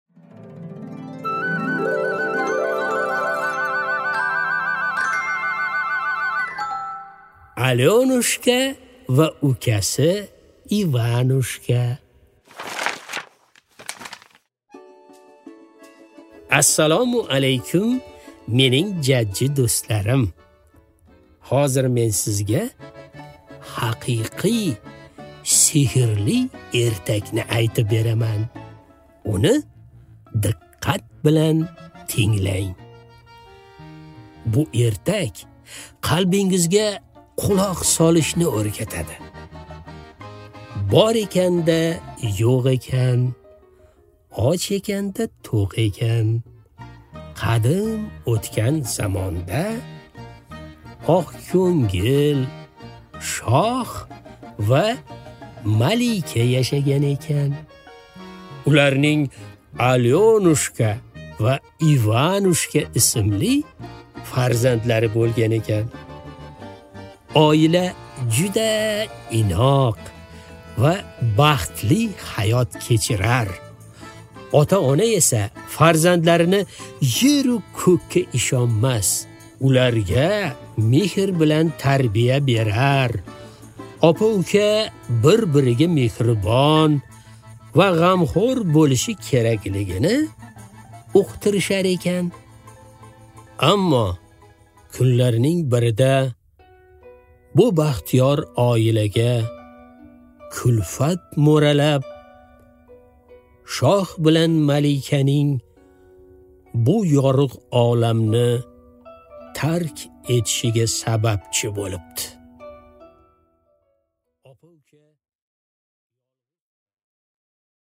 Аудиокнига Alyonushka va ukasi Ivanushka | Библиотека аудиокниг